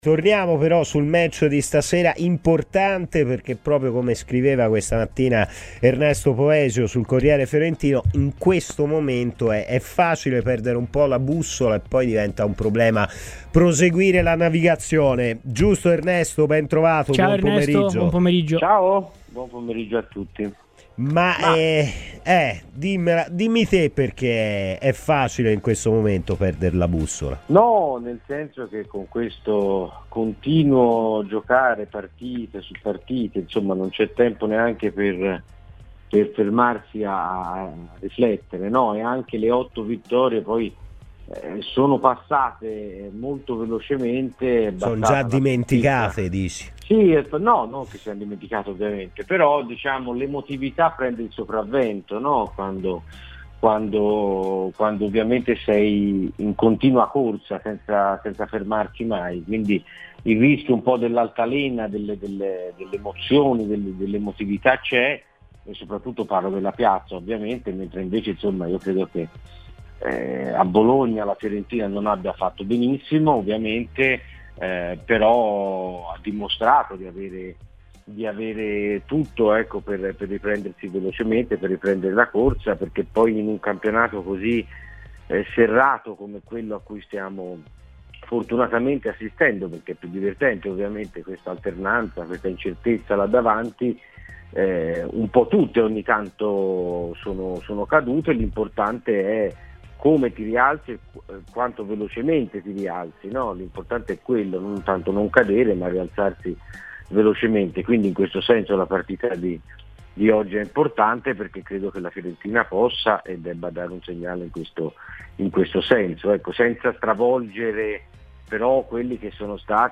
Per parlare il match di questa sera tra Fiorentina e Udinese è intervenuto ai microfoni di Radio FirenzeViola, durante “Palla al Centro”